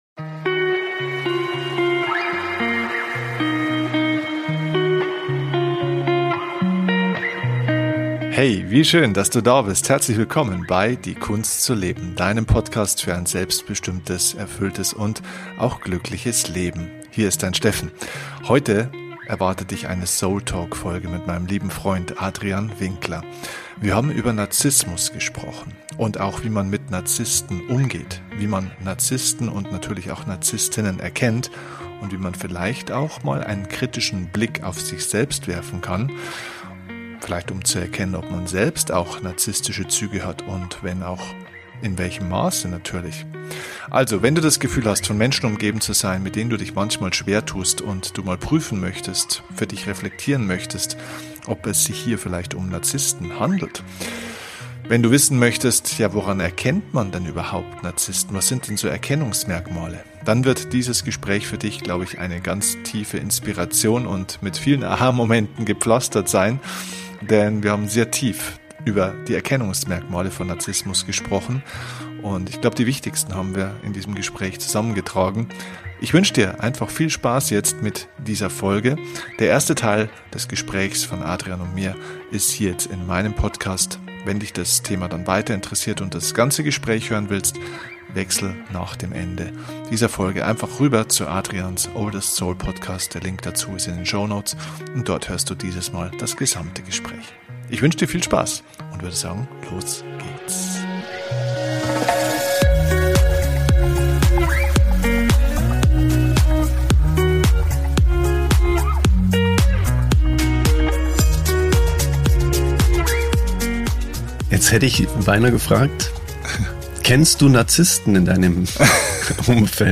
Wie immer bei diesem Format: kein Skript, nur zwei Freunde die miteinander reden.